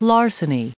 added pronounciation and merriam webster audio
985_larceny.ogg